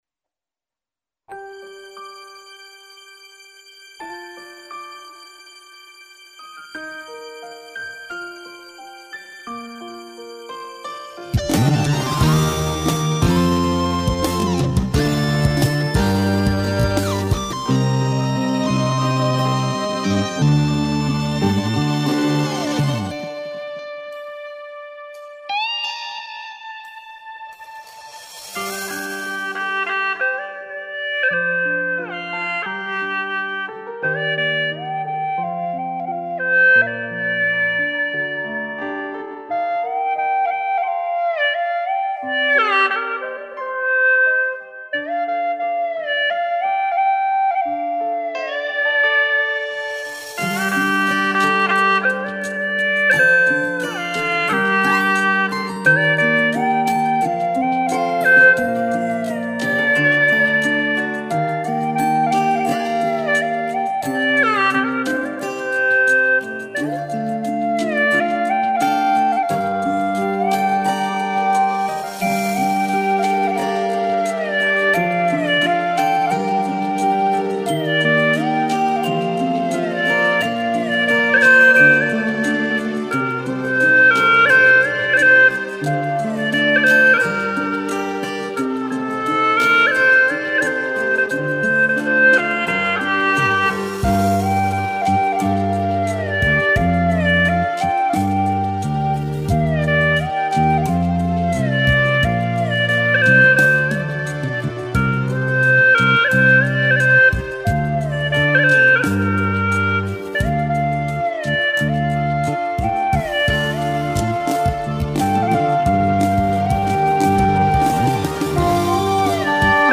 为了用能用葫芦丝演奏，我在原曲上做了些许修改，就是大家现在听到的版本。